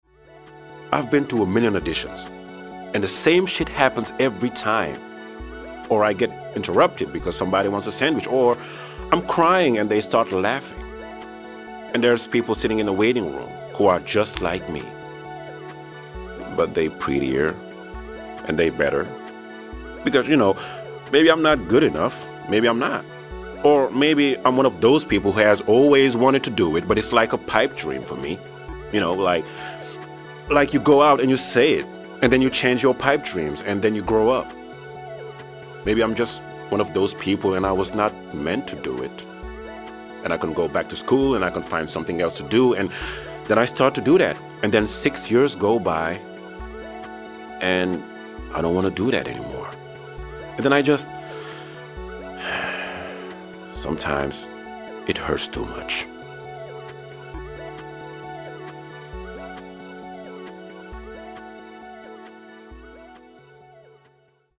Voix-off
20 - 40 ans - Baryton-basse